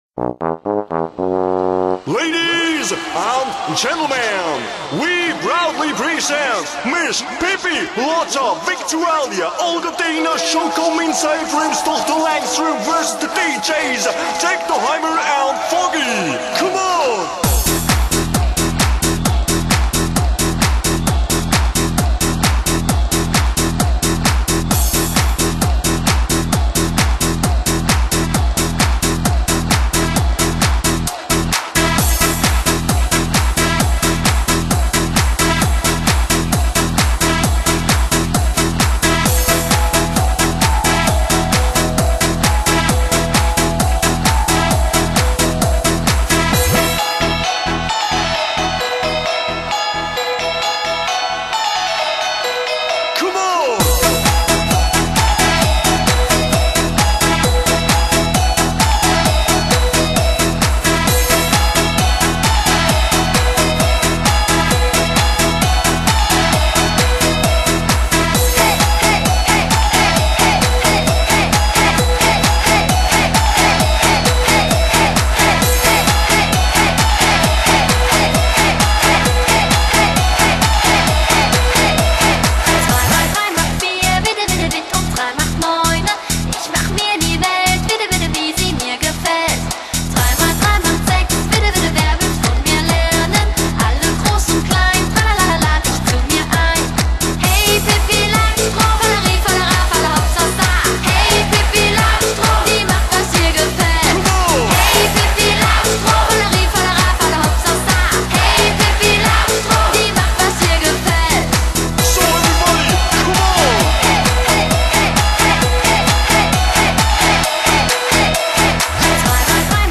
[DISCO]